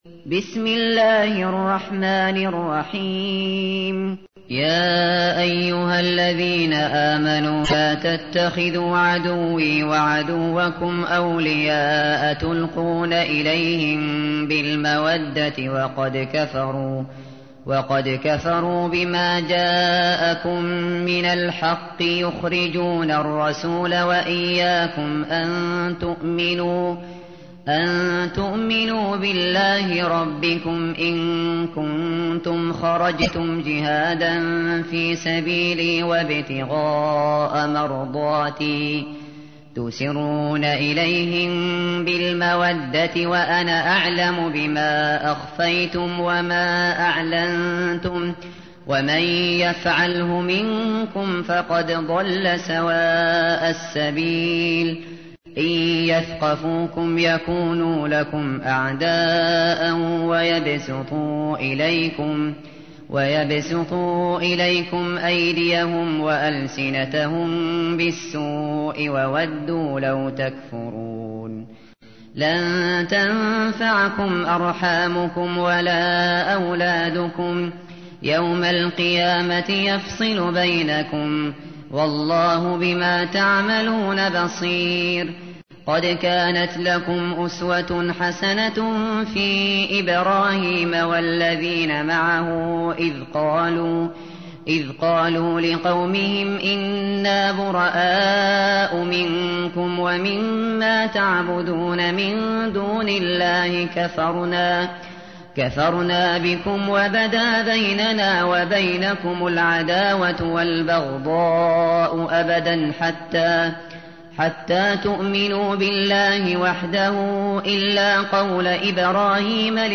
تحميل : 60. سورة الممتحنة / القارئ الشاطري / القرآن الكريم / موقع يا حسين